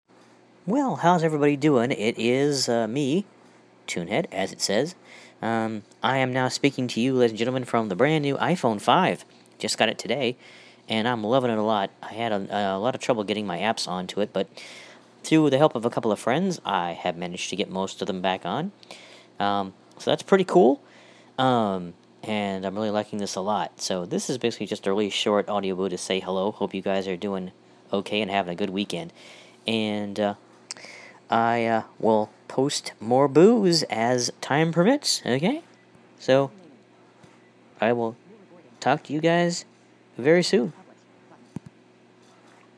First boo from the iPhone 5